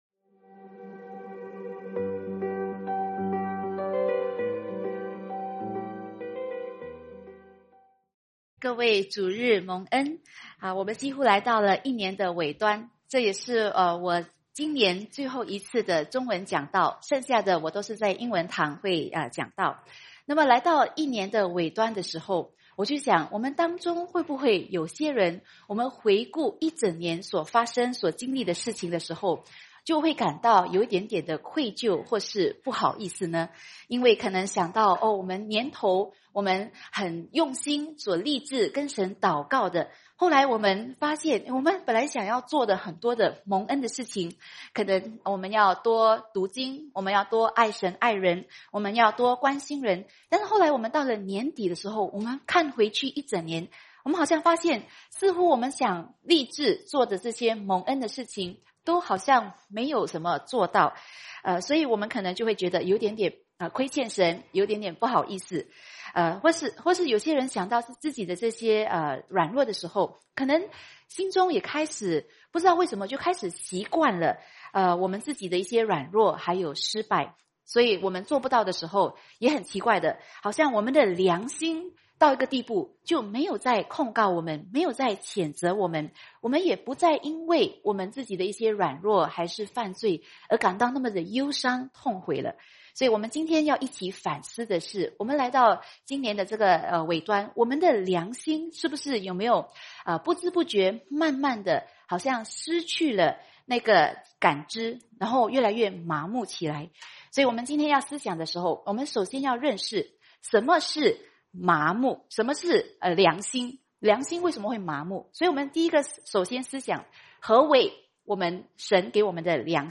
主日信息